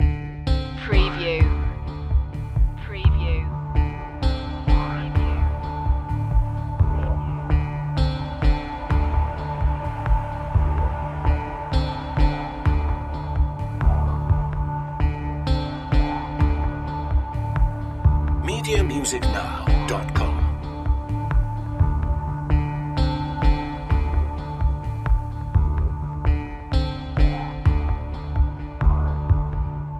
Dark reflective royalty free soundtrack music.